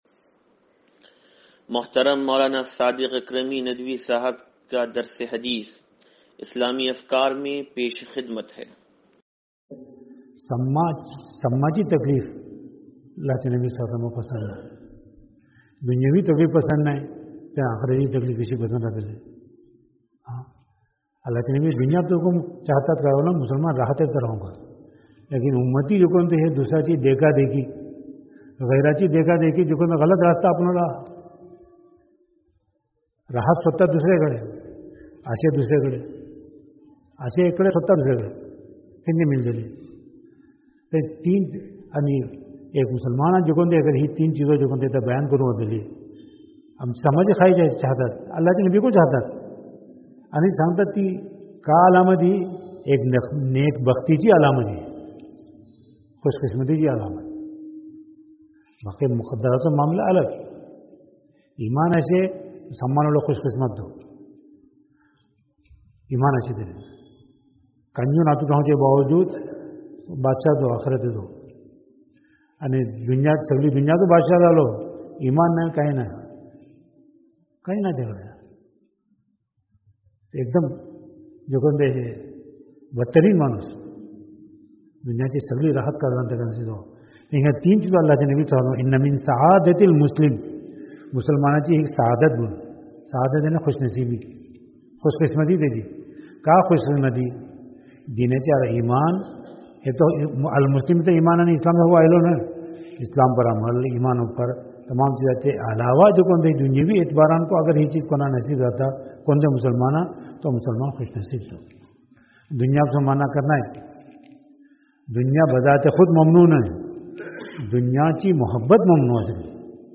درس حدیث نمبر 0630
(تنظیم مسجد)